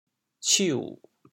潮州 la6 ciu3 潮阳 la6 ciu3 潮州 0 1 潮阳 0 1